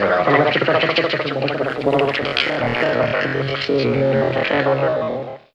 Index of /90_sSampleCDs/Zero-G - Total Drum Bass/Instruments - 3/track61 (Vox EFX)
09 Weird Speak.wav